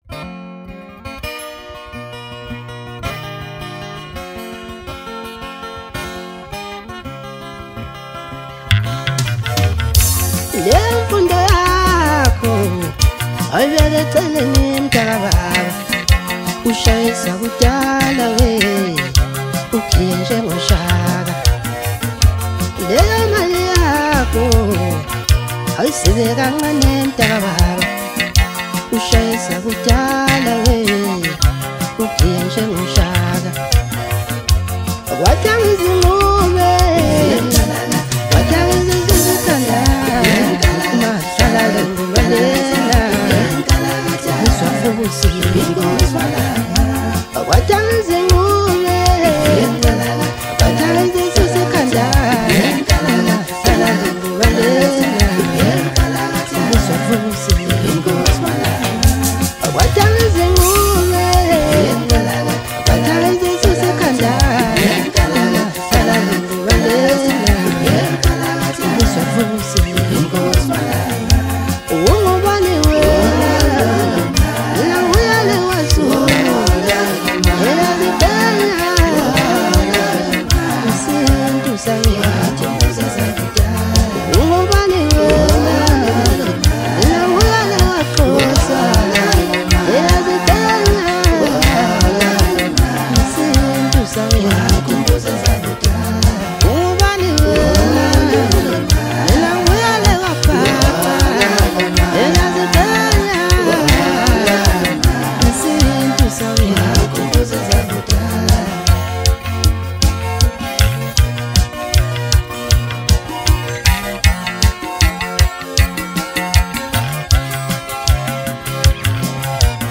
Home » Maskandi » Maskandi Music